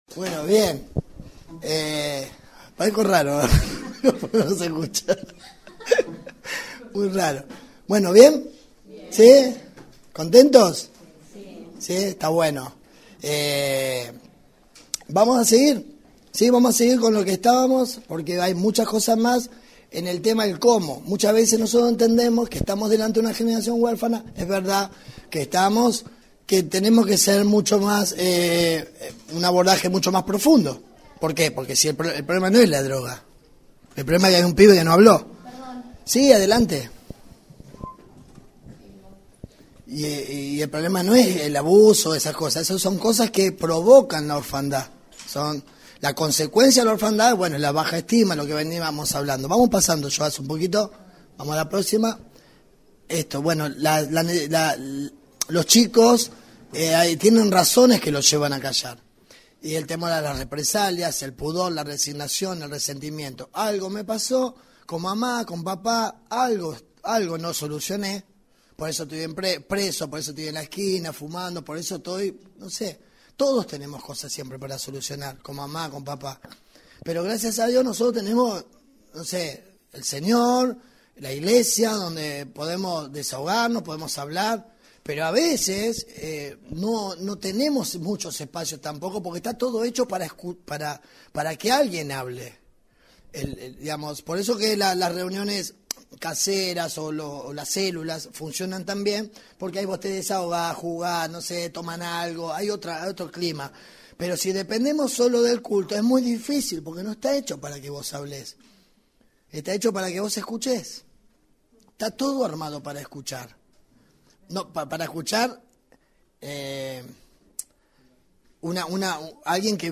Descargá el mensaje haciendo click aquí